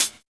Trebly Closed High Hat Sound E Key 10.wav
Royality free hi hat one shot tuned to the E note. Loudest frequency: 10266Hz
trebly-closed-high-hat-sound-e-key-10-Ktx.ogg